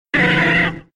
Cri de Machopeur K.O. dans Pokémon X et Y.